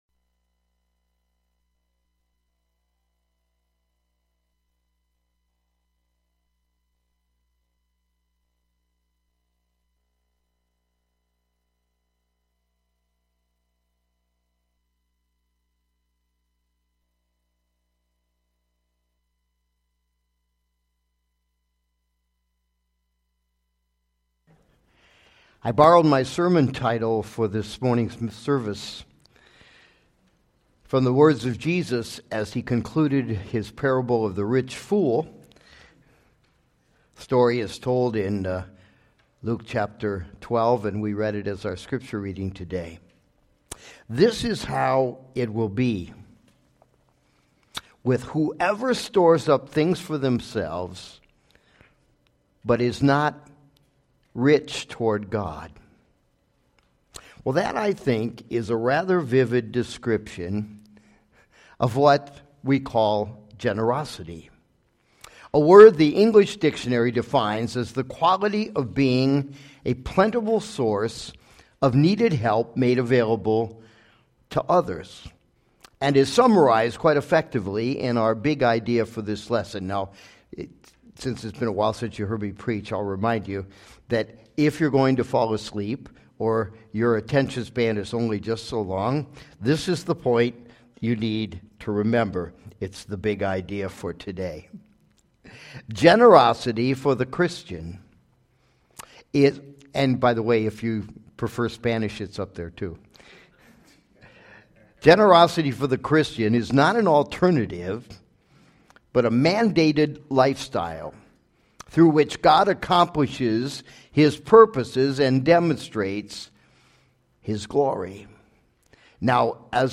Sermons by Calvary Memorial Church Rockford